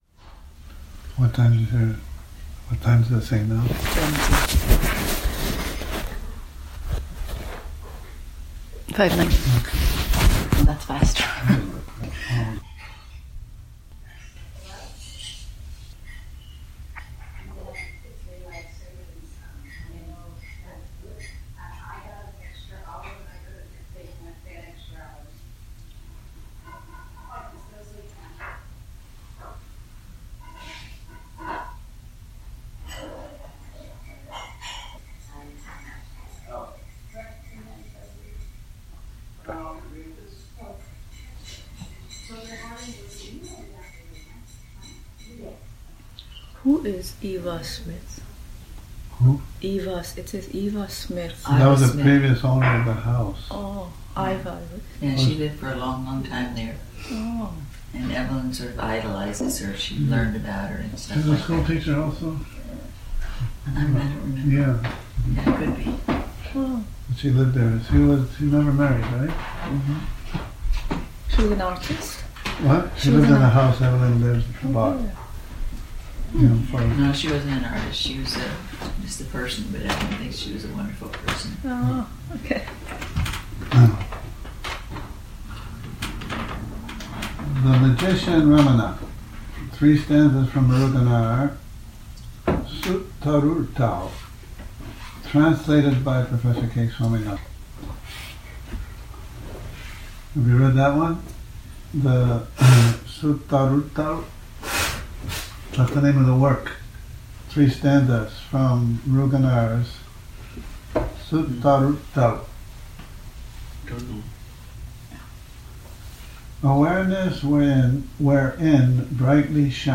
Morning Reading, 03 Nov 2019
a morning reading starting with 'The Magician Ramana' by Muruganar Jan 1966 issue of 'The Mountain Path', pp.21...,